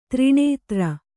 ♪ triṇētra